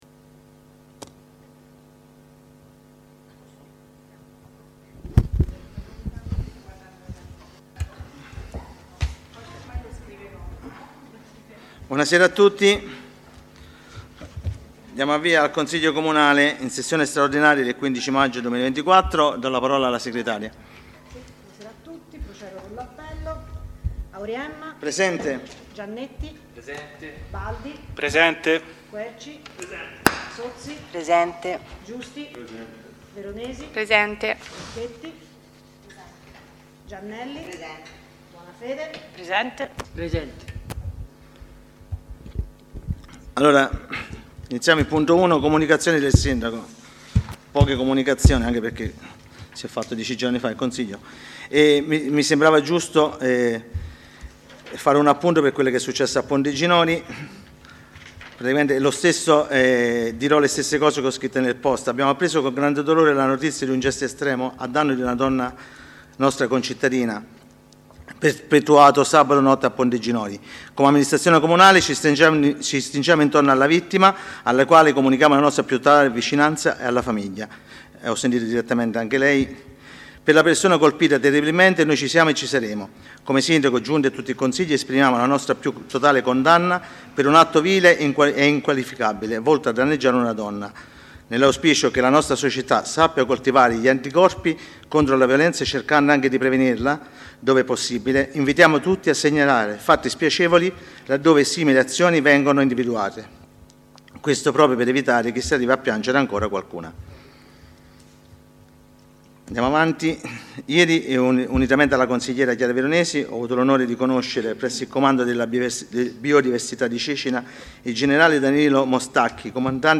download di registrazione audio seduta consiglio comunale